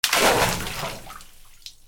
水に落とす 水 飛び込む
『ザボン』